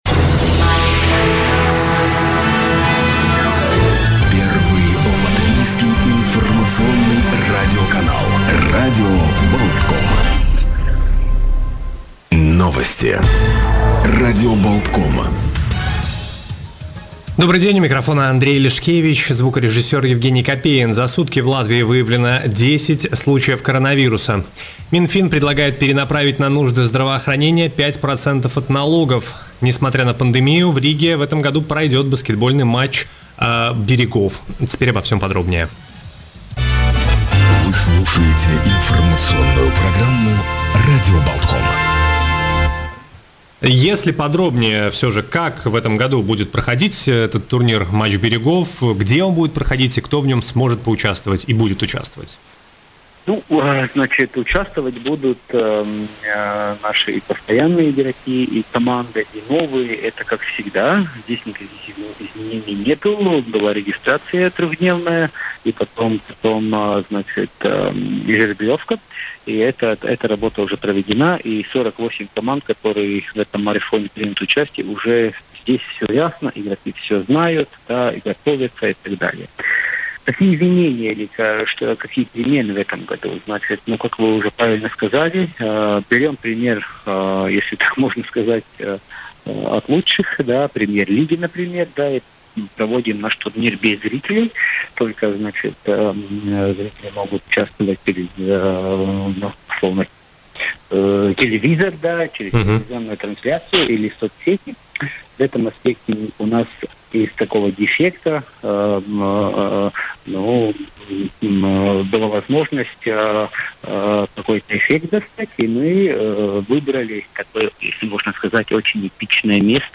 рассказал в эфире радио Baltkom